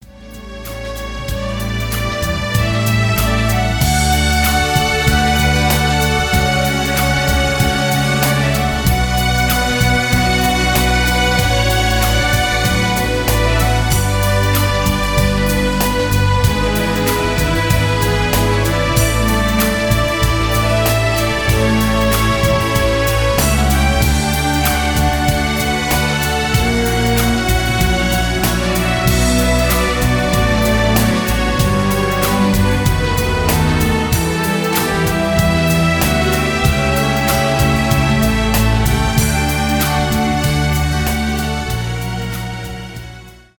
поп , инструментальные
романтические
без слов